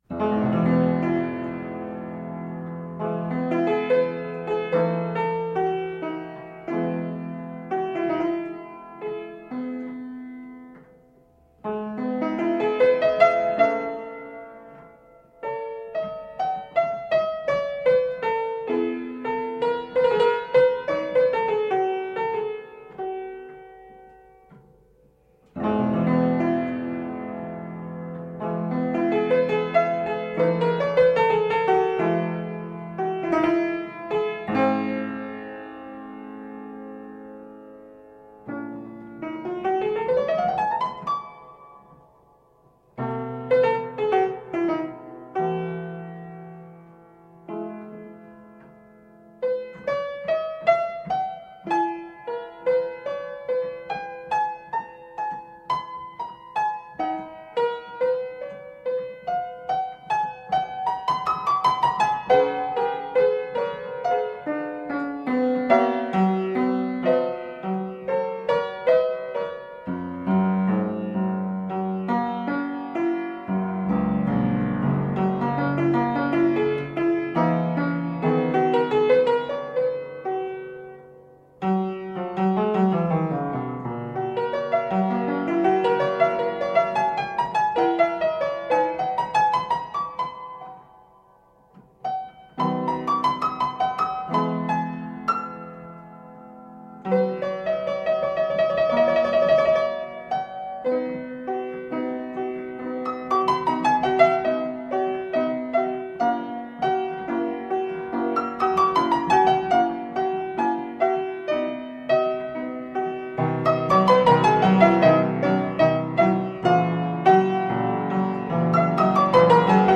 Marvelously played classical piano pieces.
Tagged as: Classical, Instrumental Classical, Piano